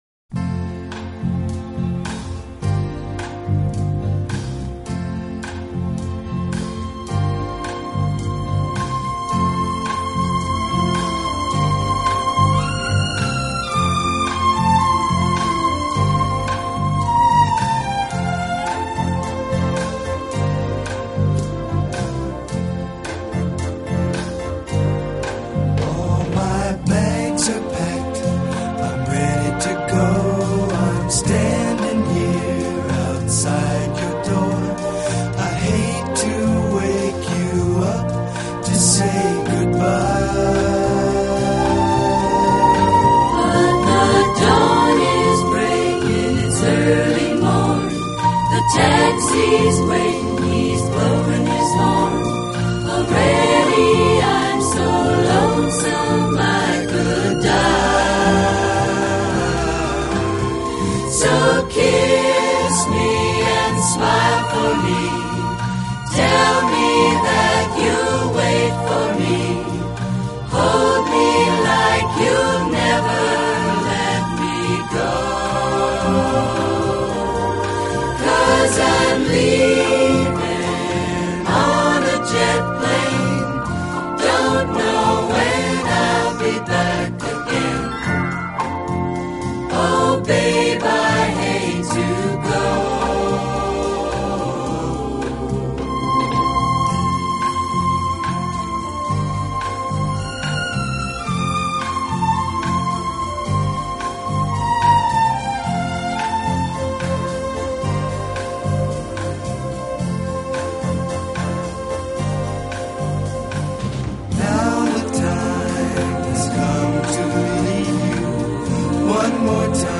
【轻音乐专辑】